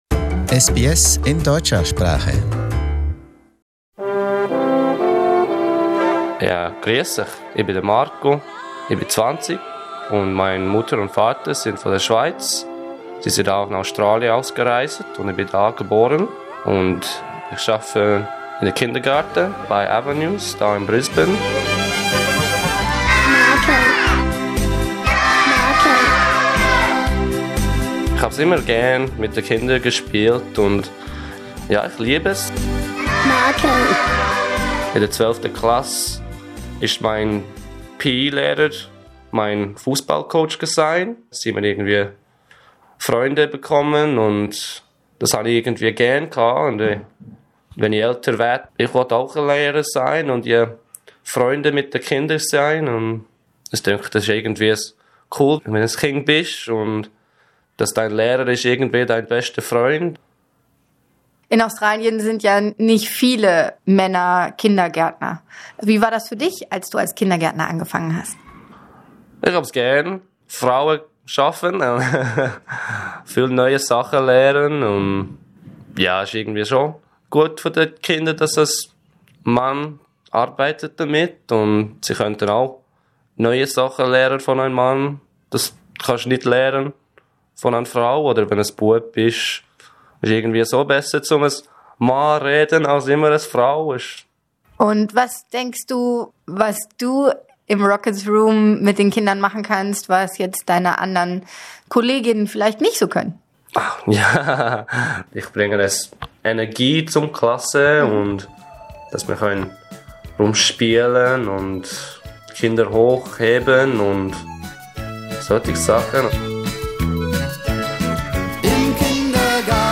beim Interview